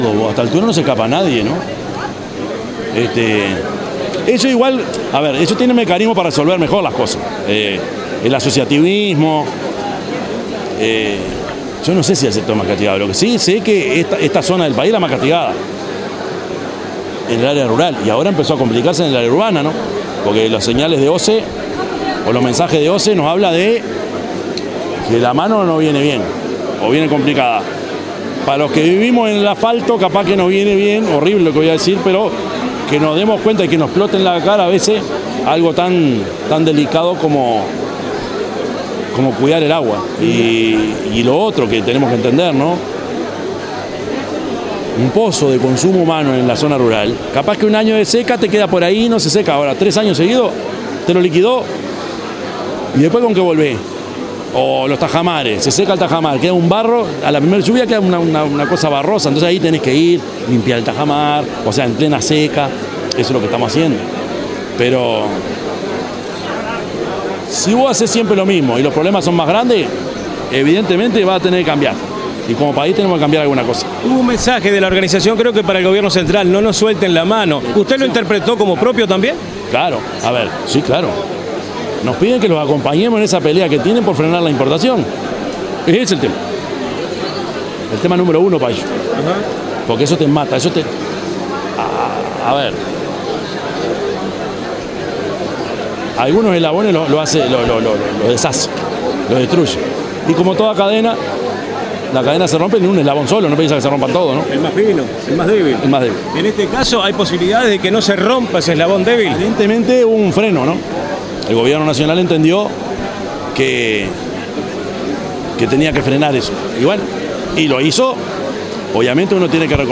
Intendente Yamandú Orsi estuvo presente en la inauguración de la Expo Avícola 2023
yamandu_orsi_-_intendente_de_canelones_8.mp3